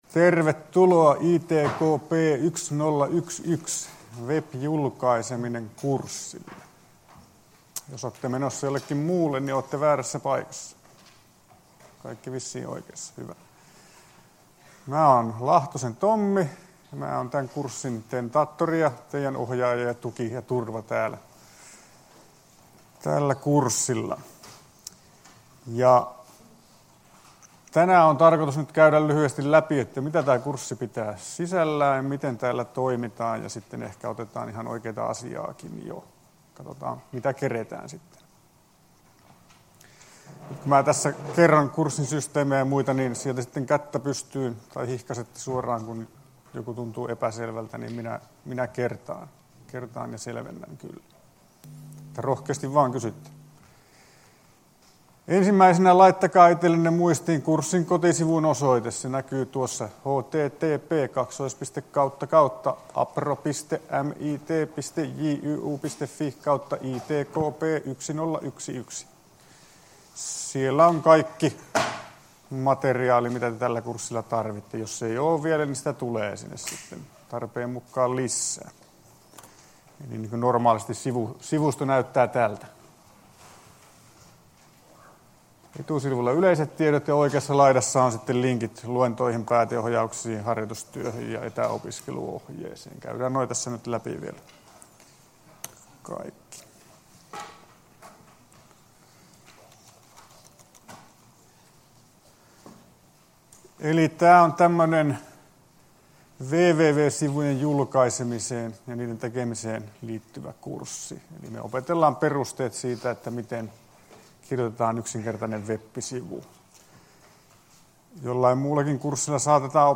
Luento 12.9.2018 — Moniviestin